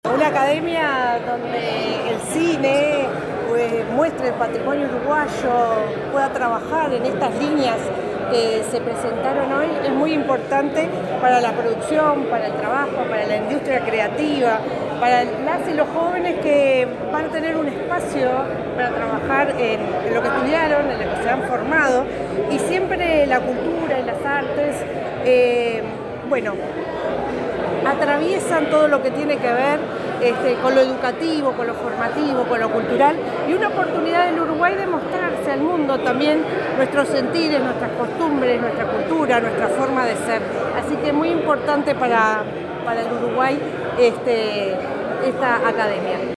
Declaraciones de la ministra interina de Educación y Cultura, Gabriela Verde
Declaraciones de la ministra interina de Educación y Cultura, Gabriela Verde 17/09/2025 Compartir Facebook X Copiar enlace WhatsApp LinkedIn Luego de la presentación de la Academia de Artes y Ciencias Cinematográficas del Uruguay, la ministra interina de Educación y Cultura, Gabriela Verde, dialogó con la prensa sobre la importancia de esta organización.